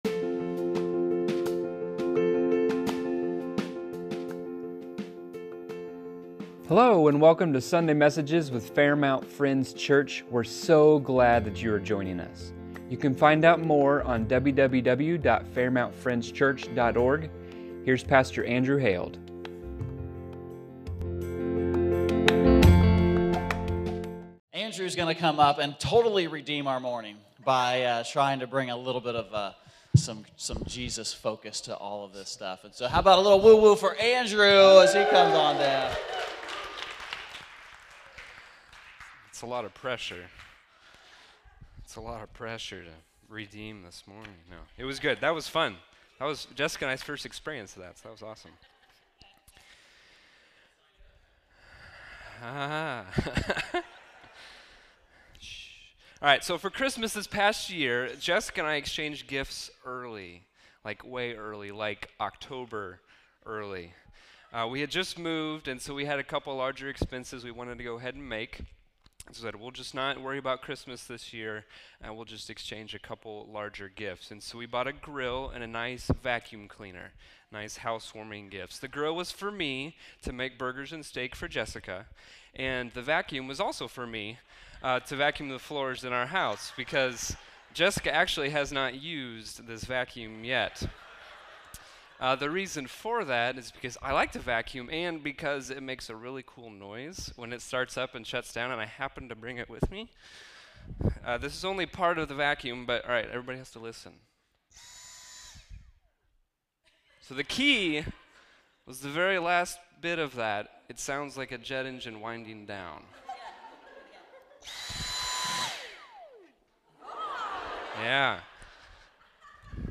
Sunday Messages | Fairmount Friends Church